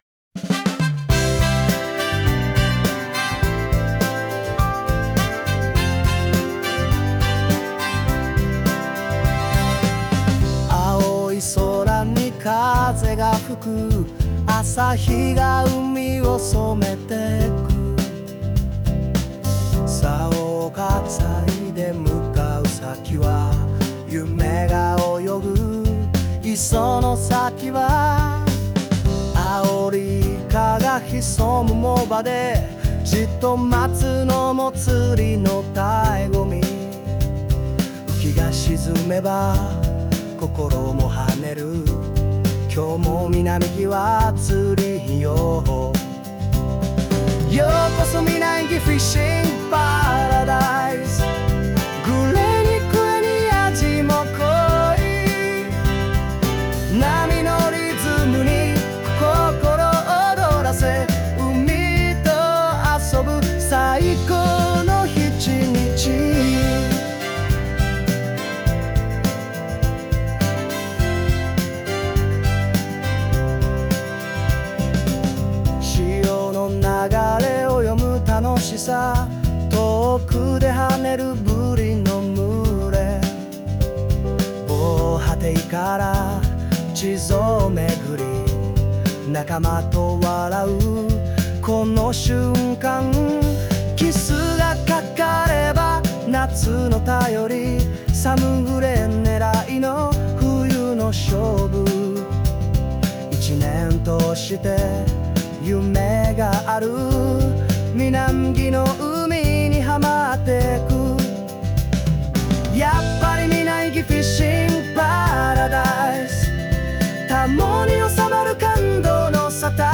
ChatGPTで作詞・SunoAIで作曲しています。